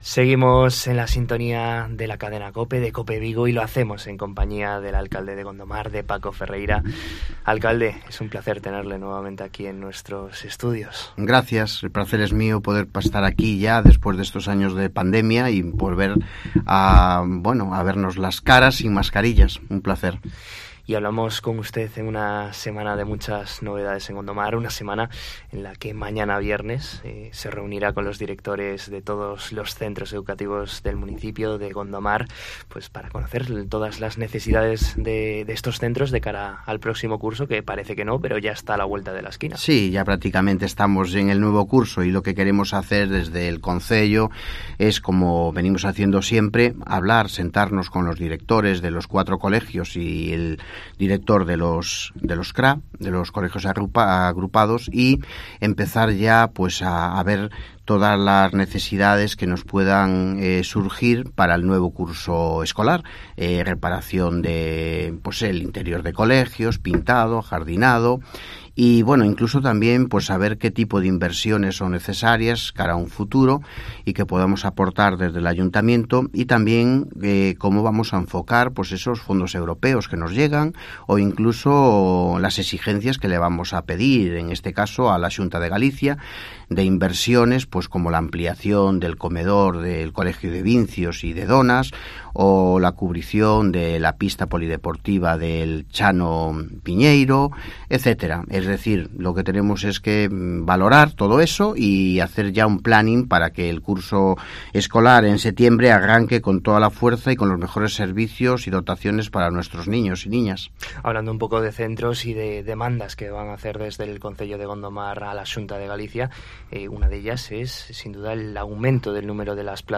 En COPE Vigo hemos hablado con el alcalde de Gondomar, Paco Ferreira, para conocer la actualidad de este municipio del sur de la provincia de Pontevedra